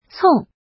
怎么读
cong4.mp3